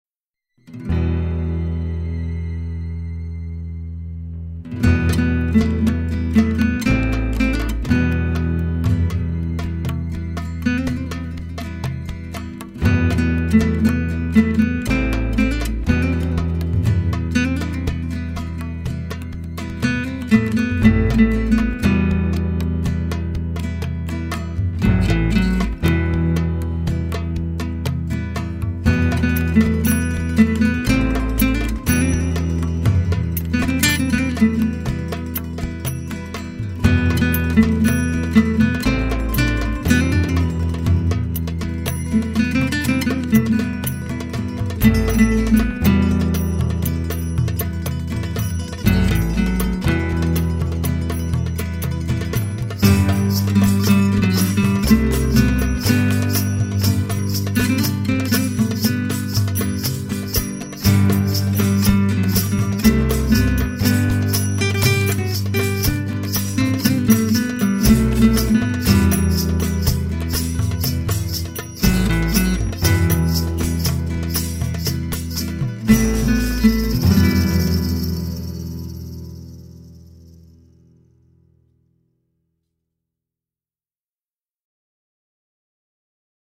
MOODY